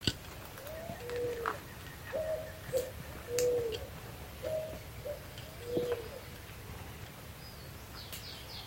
Pomba-do-orvalho (Patagioenas maculosa)
Nome em Inglês: Spot-winged Pigeon
País: Argentina
Condição: Selvagem
Certeza: Observado, Gravado Vocal
Paloma-manchada_1.mp3